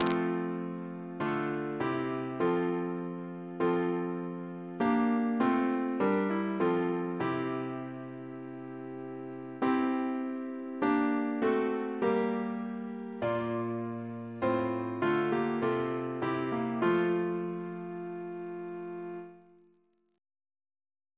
Double chant in F Composer: Richard Langdon (1729-1803) Reference psalters: ACB: 135; CWP: 184; OCB: 24; PP/SNCB: 27; RSCM: 86